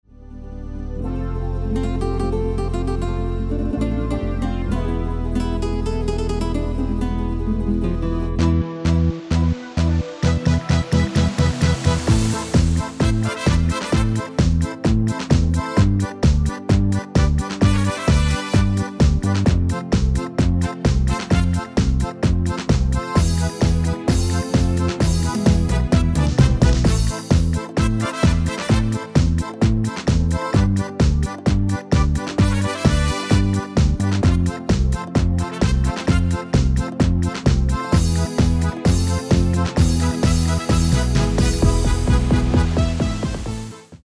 Demo/Koop midifile
Genre: Nederlands amusement / volks
Toonsoort: Am
- Vocal harmony tracks